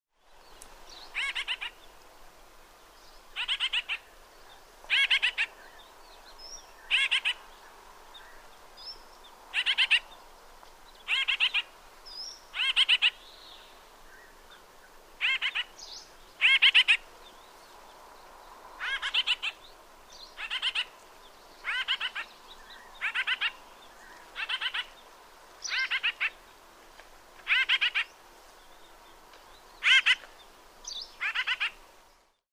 white-winged-grosbeak-call